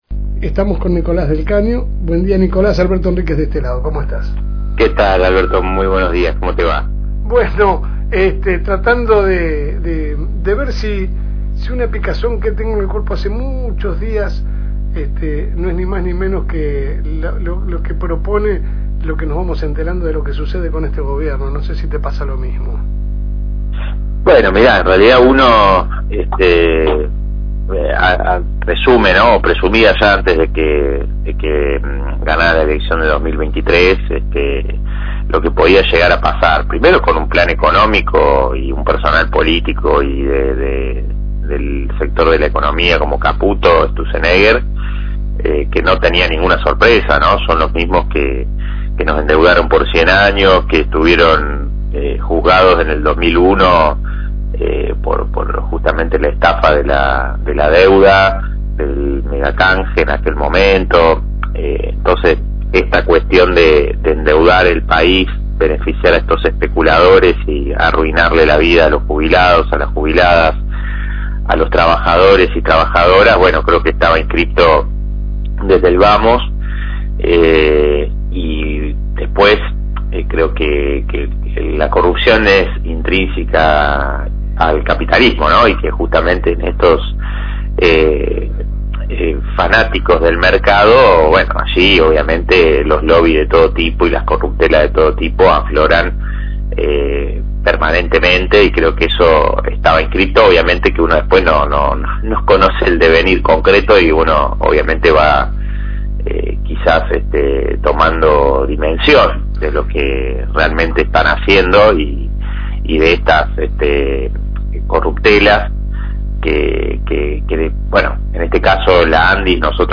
Esta vez nos toco charlar con Nicolas del Caño, candidato por el Frente de Izquierda de los Trabajadores Unidad (FITU) por la tercer sección electoral, por lo que los lobenses tienen la oportunidad de votarlo.